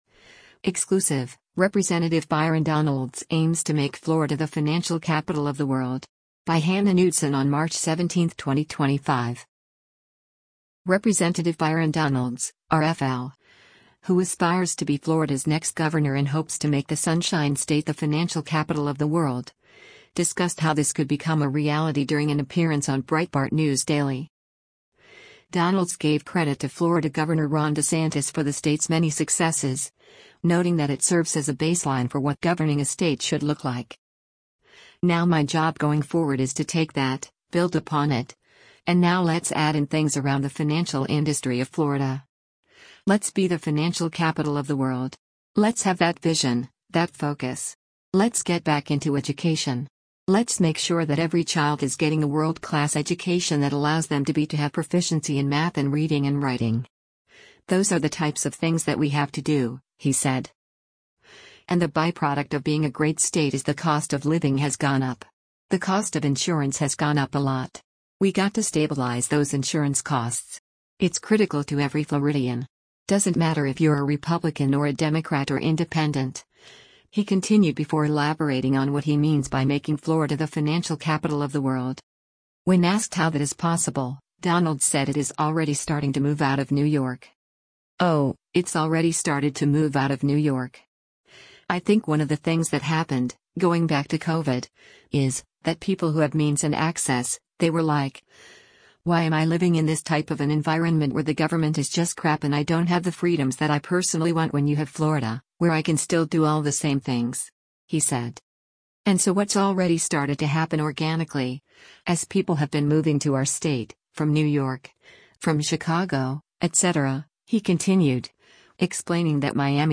Rep. Byron Donalds (R-FL), who aspires to be Florida’s next governor and hopes to make the Sunshine State the “financial capital of the world,” discussed how this could become a reality during an appearance on Breitbart News Daily.